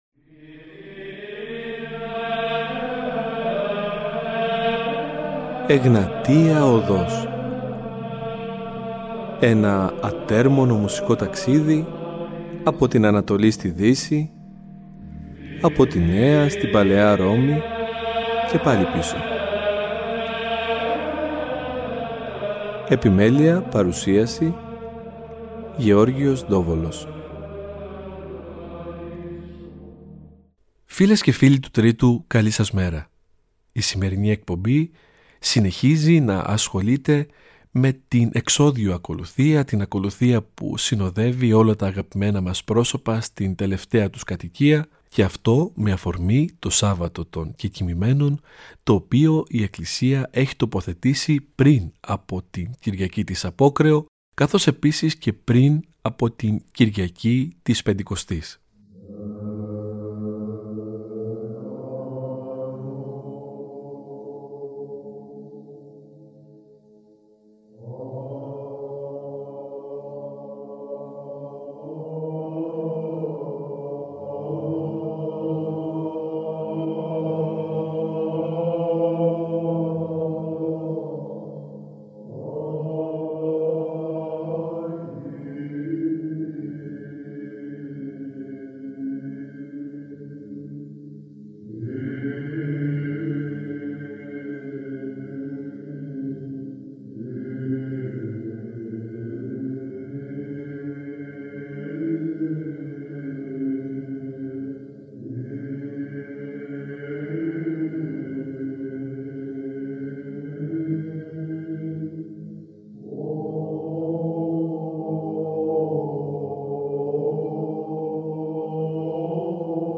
Στις δύο εκπομπές ακούγονται ξεχωριστές ερμηνείες από βυζαντινούς χορούς αλλά και παραδοσιακούς ψάλτες που θα αποδώσουν τον Άμμωμο, τα Ευλογητάρια, τους νεκρώσιμους Μακαρισμούς, τα Ιδιόμελα, αλλά και άγνωστα μαθήματα που έχουν κατά καιρούς συνθέσει καταξιωμένοι βυζαντινοί και μεταβυζαντινοί μελοποιοί.